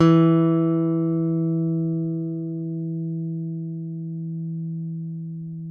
ALEM FING E3.wav